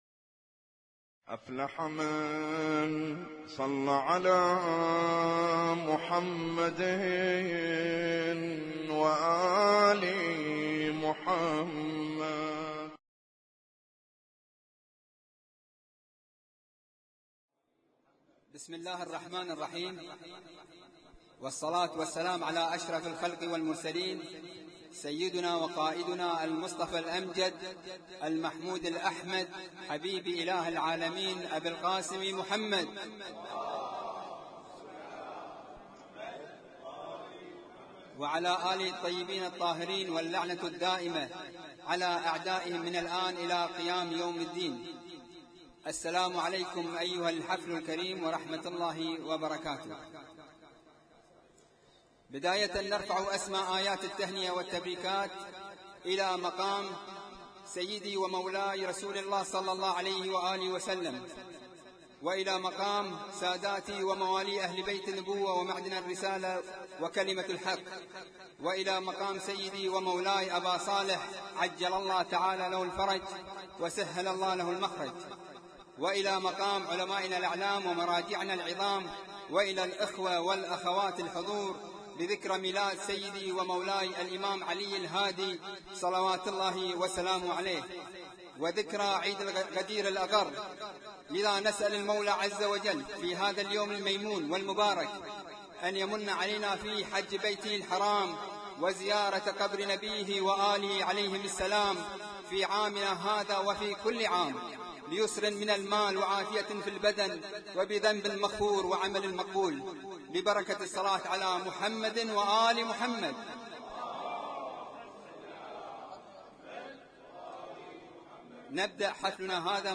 Husainyt Alnoor Rumaithiya Kuwait
اسم التصنيف: المـكتبة الصــوتيه >> المواليد >> المواليد 1436